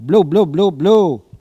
Cri pour appeler les brebis ( prononcer le cri )
Langue Maraîchin